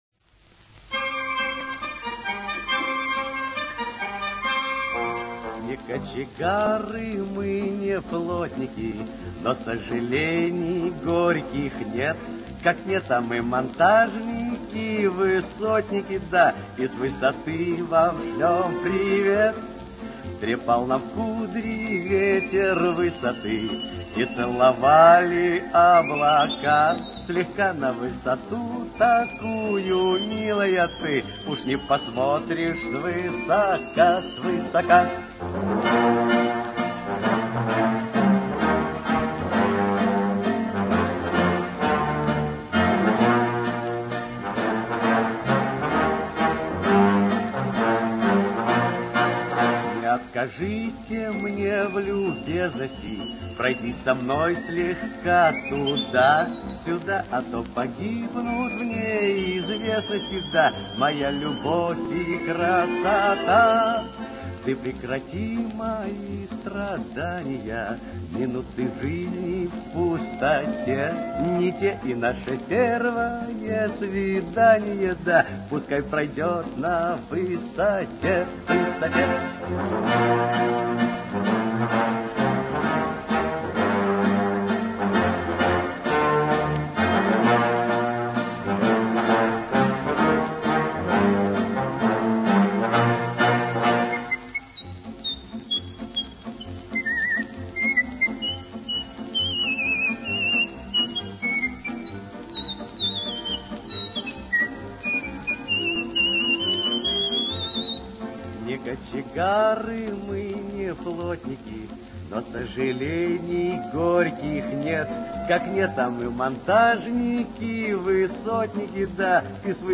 Худ. свист